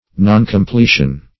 Noncompletion \Non`com*ple"tion\, n.